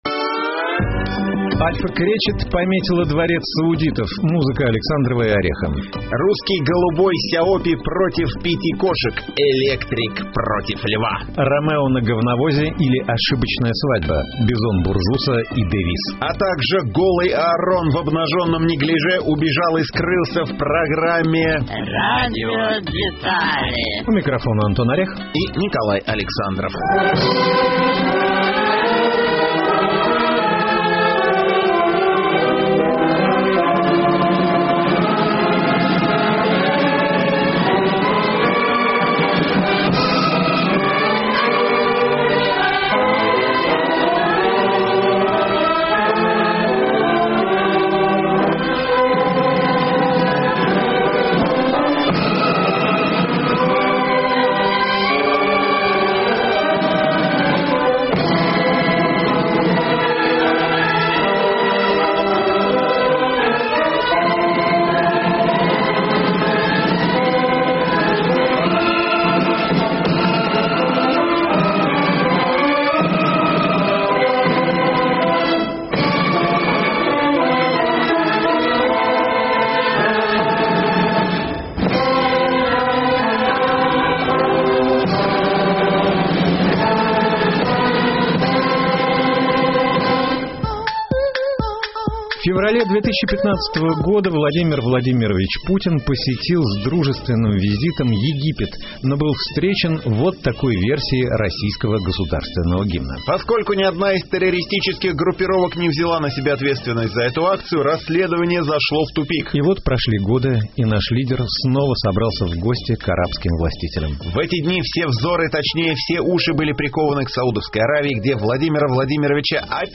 У микрофона Антон Орех